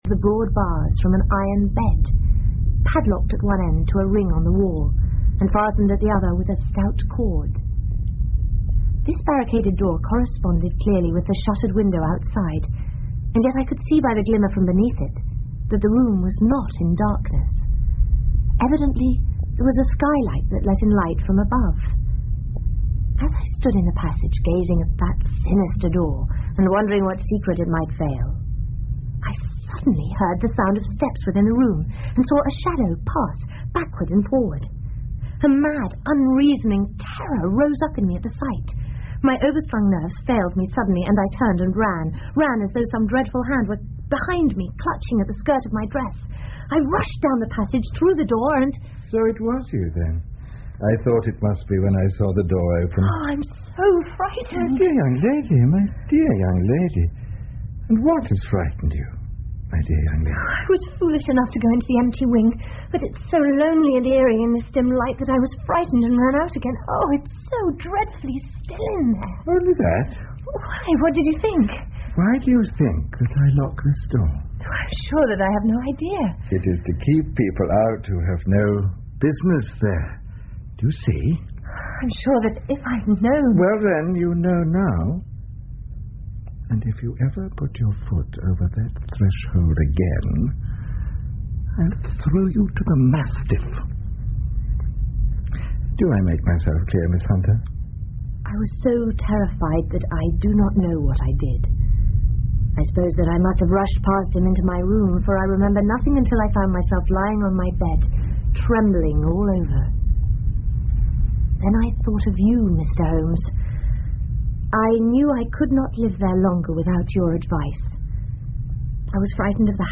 福尔摩斯广播剧 The Copper Beeches 7 听力文件下载—在线英语听力室